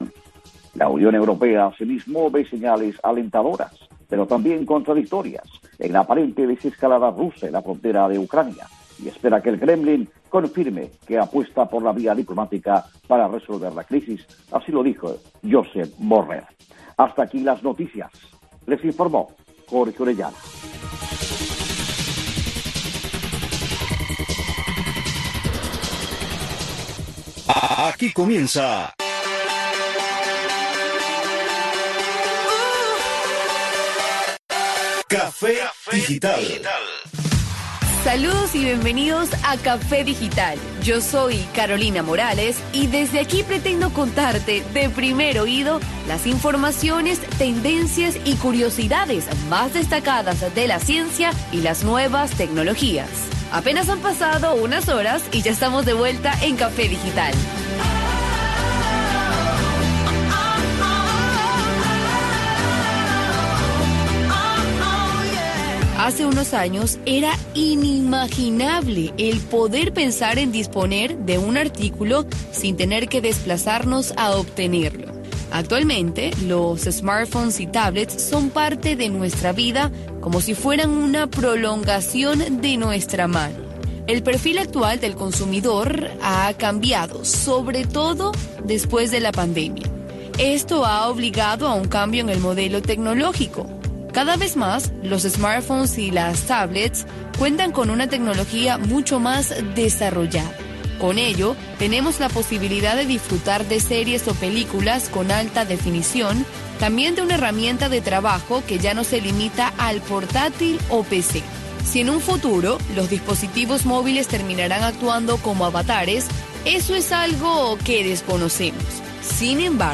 Café digital es un espacio radial que pretende ir más allá del solo objetivo de informar sobre nuevos avances de la ciencia y la tecnología.
Café digital traerá invitados que formen parte de la avanzada científica y tecnológica en el mundo y promoverá iniciativas e ideas que puedan llevar a cabo los jóvenes dentro de Cuba para dar solución a sus necesidades más cotidianas.